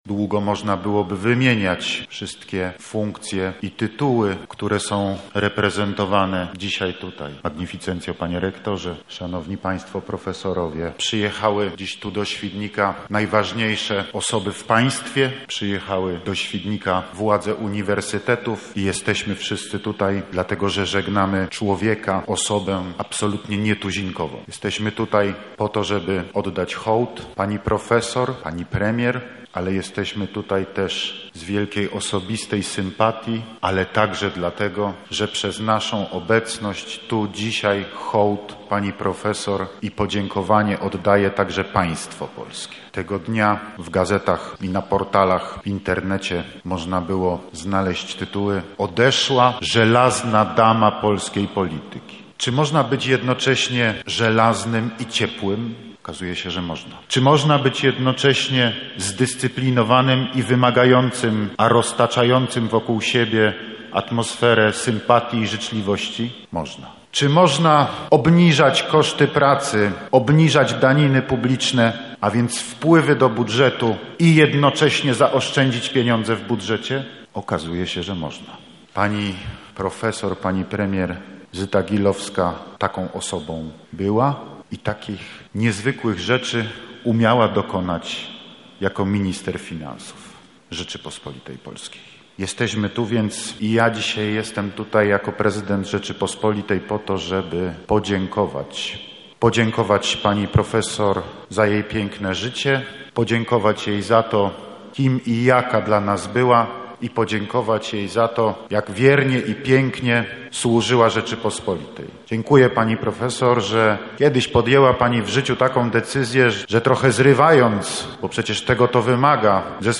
Pogrzeb Gilowskiej 2
– tak podczas pogrzebu wspominał profesor Gilowską prezydent Andrzej Duda